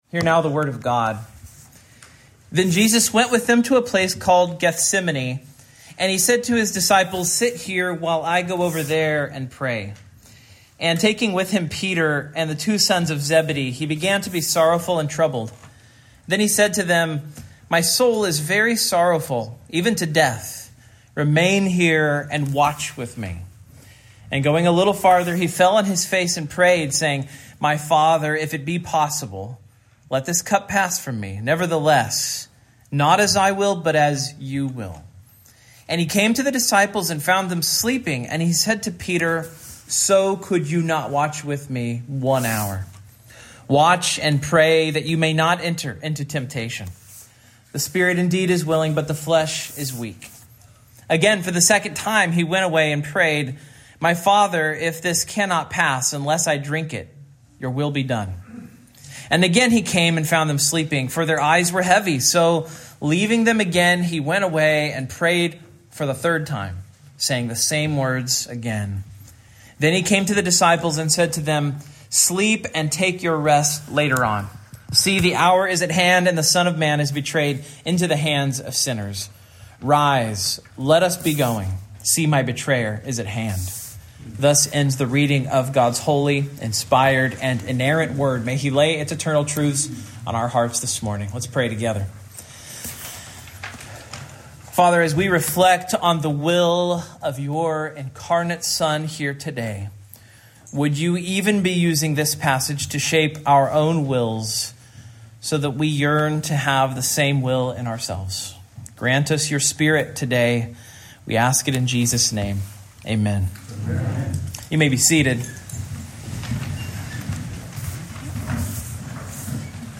Service Type: Morning